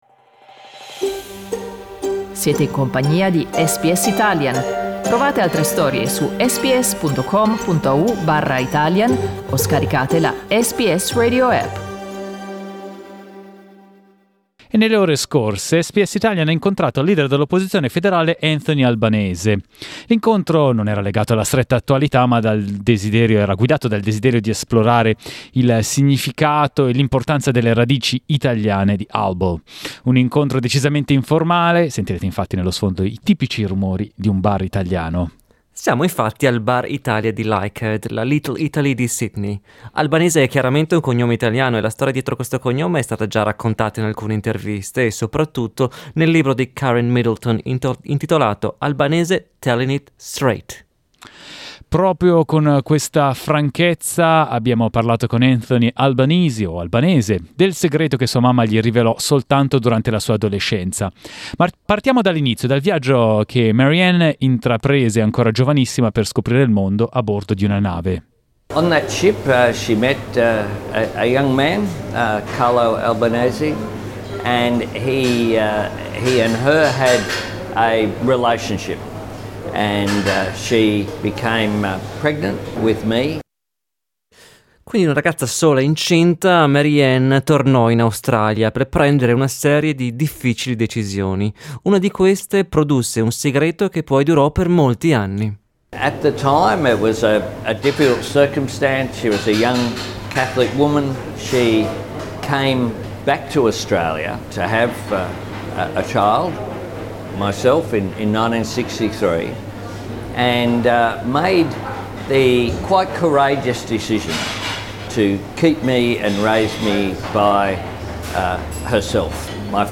Abbiamo incontrato Anthony Albanese al Bar Italia di Leichhardt per un caffè e una chiacchierata informale sulla sua storia personale e la riscoperta della importanza della sua identità italiana.